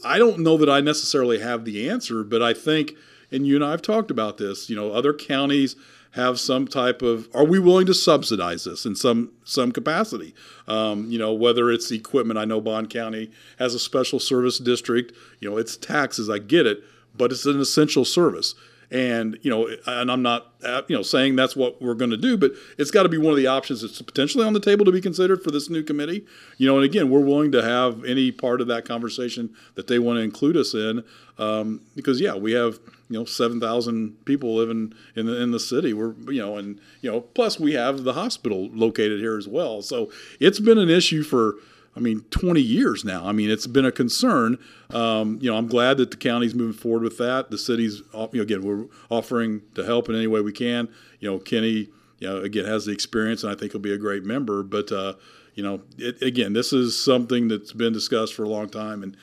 Mayor Knebel says that may be something to at least consider going forward.